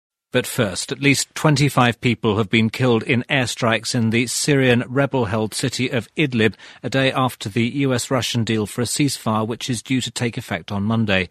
【英音模仿秀】叙利亚停火协议 支持和怀疑参半 听力文件下载—在线英语听力室